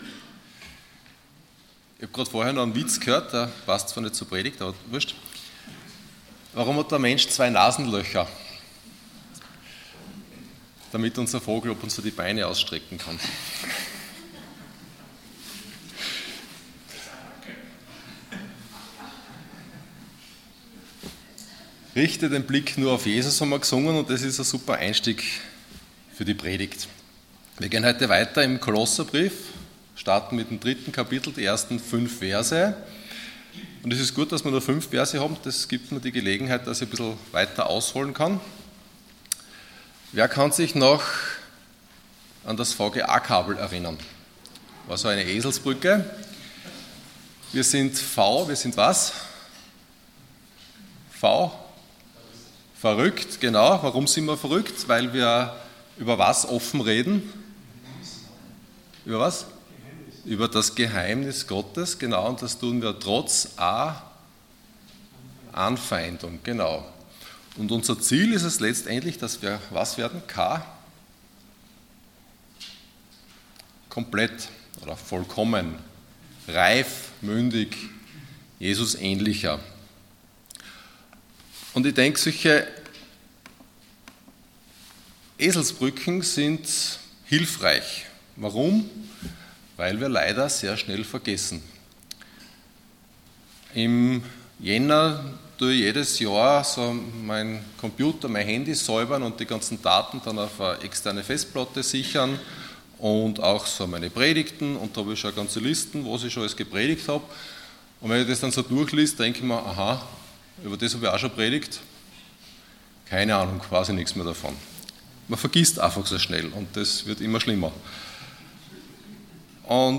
Passage: Kolosser 3,1-5 Dienstart: Sonntag Morgen